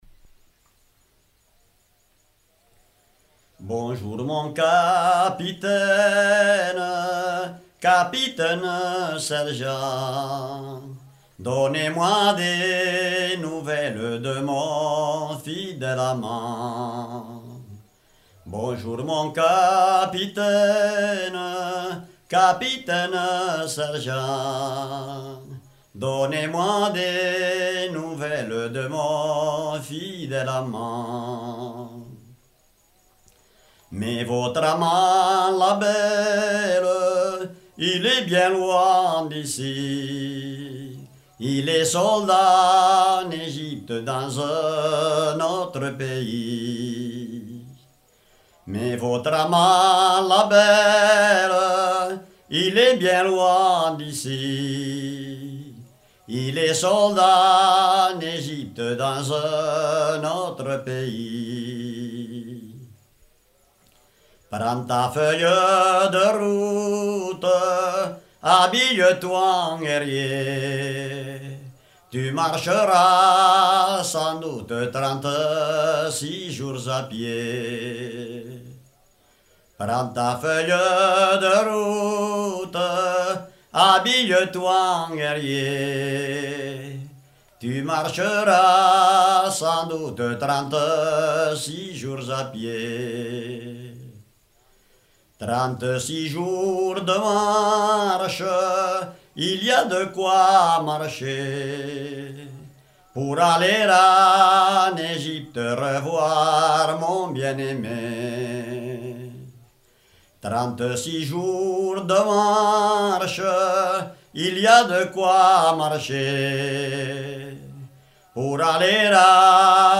Aire culturelle : Couserans
Département : Ariège
Genre : chant
Effectif : 1
Type de voix : voix d'homme
Production du son : chanté